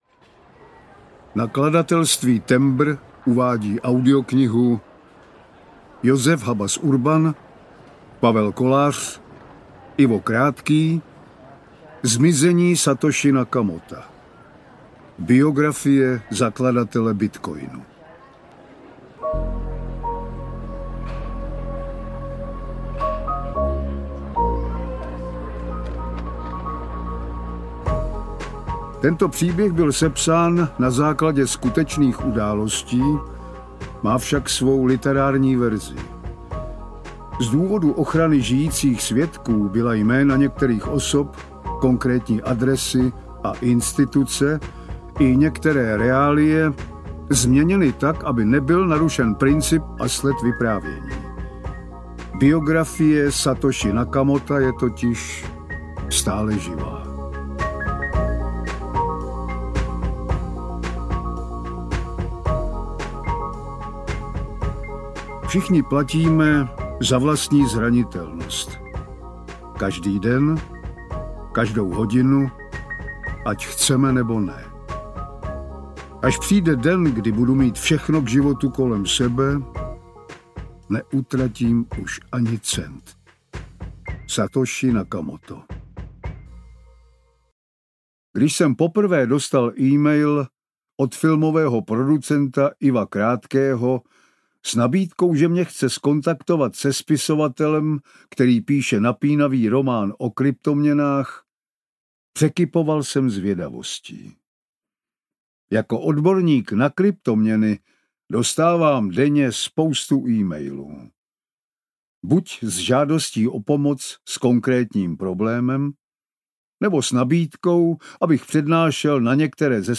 Zmizení Satoshi Nakamota audiokniha
Ukázka z knihy